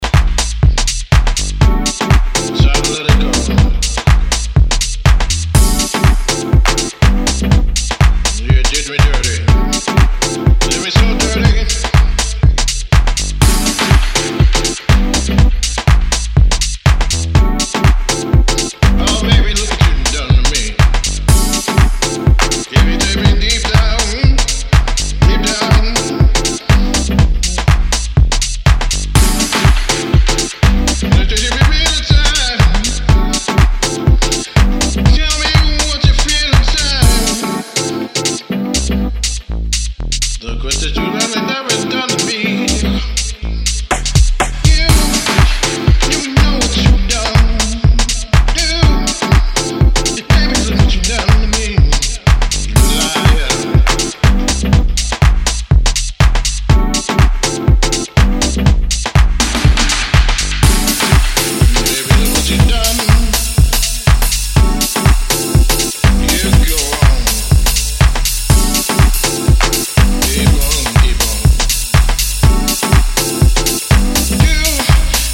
packed with soul and delivered with a weighty bottom end